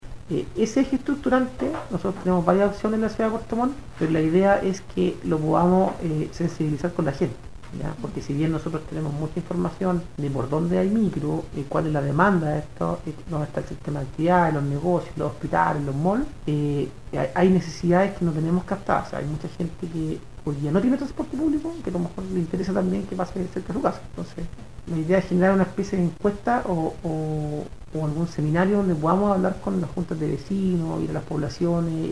Así lo explicó, Nicolás Céspedes, Seremi de Transportes y Telecomunicaciones.